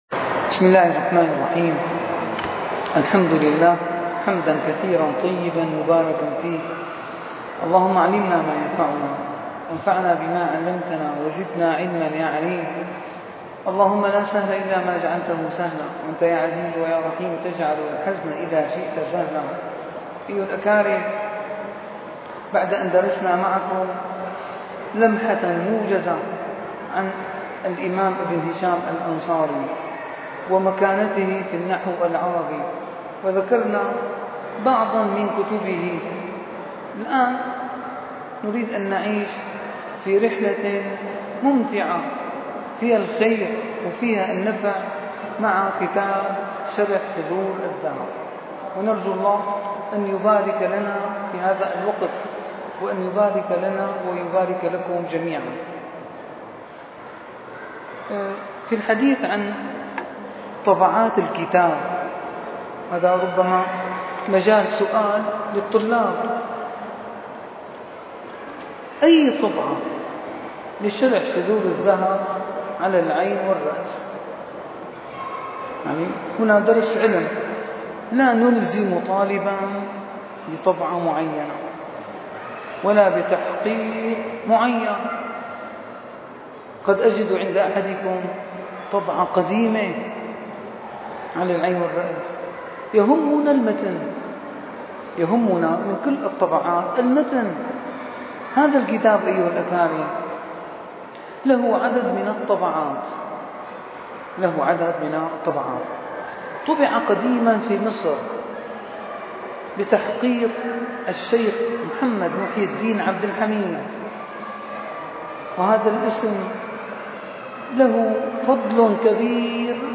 - الدروس العلمية - شرح كتاب شذور الذهب - 2- شرح كتاب شذور الذهب: الحديث عن النسخ المحققة للكتاب والمعاني اللغوية للكلمة